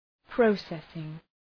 Shkrimi fonetik {‘prɒsesıŋ}